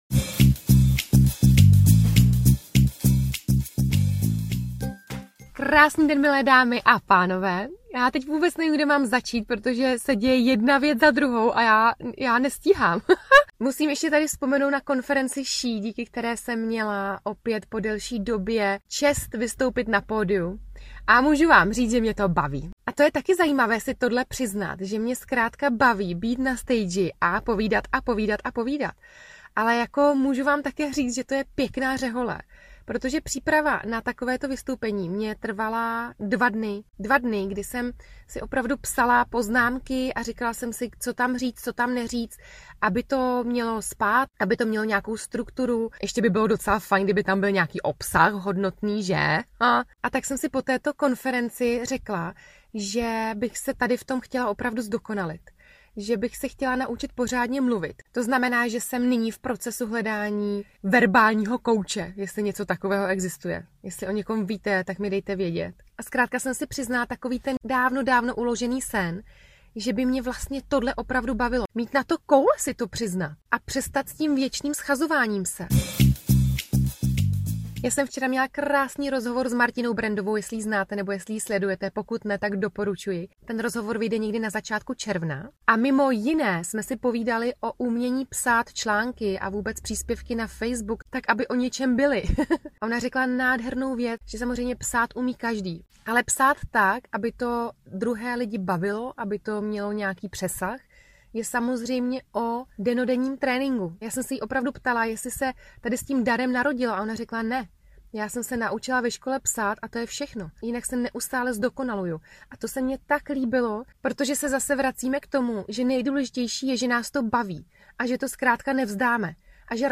Záznam z happeningu SHE 2018